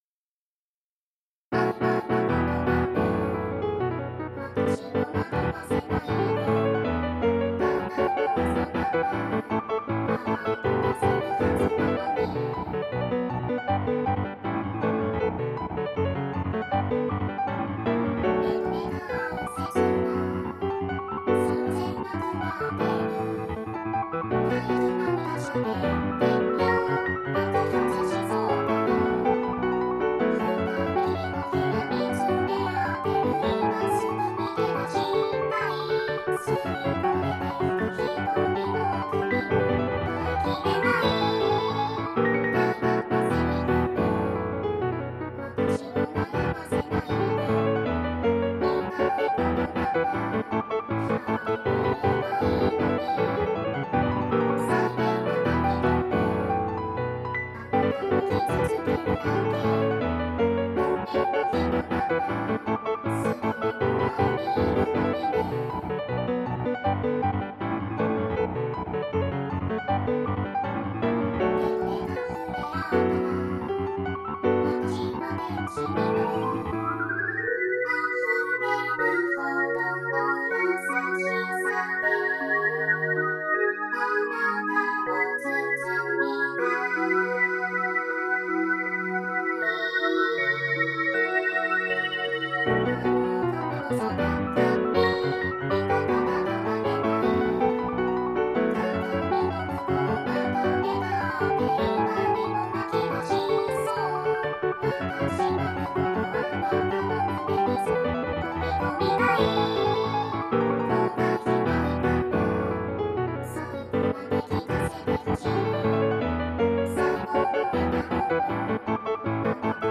【オフボーカル音源（mp3）】